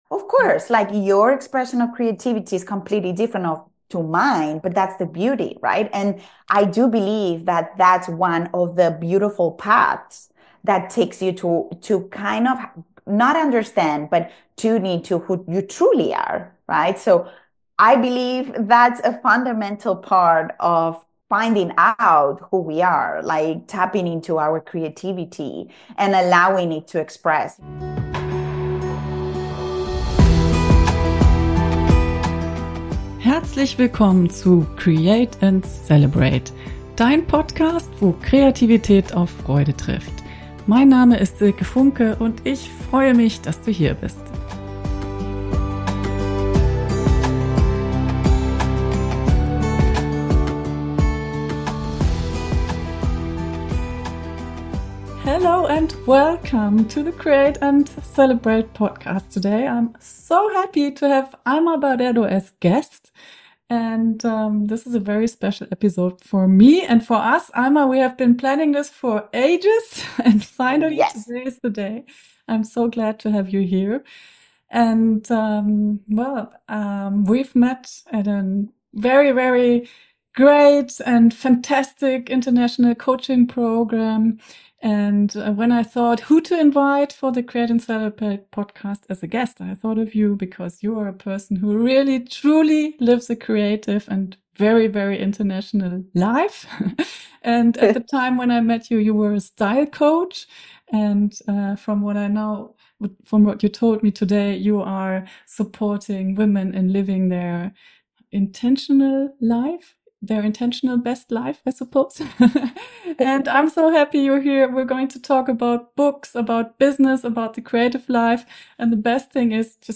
This podcast-talk is intended to activate YOU to take your wishes and creative/business projects seriously and to make room for what lights you up.